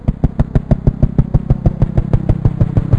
WEAPON
1 channel